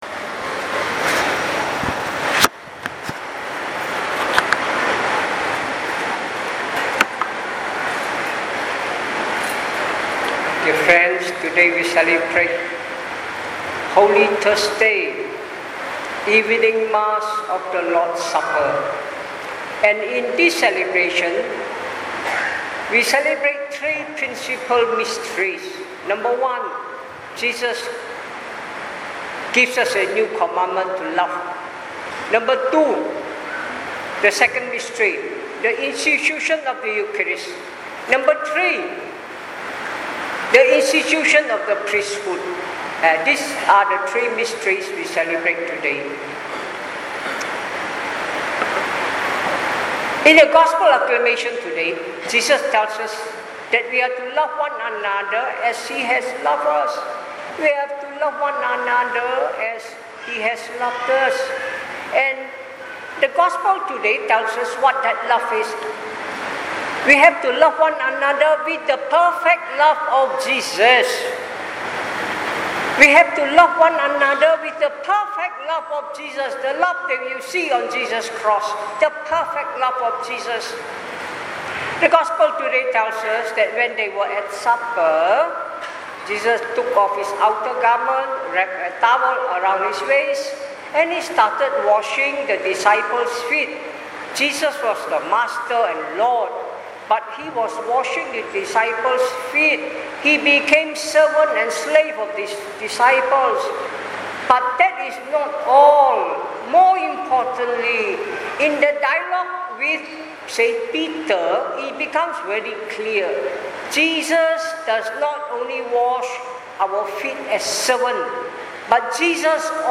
Holy Thursday – 18th April 2019 – English Audio Homily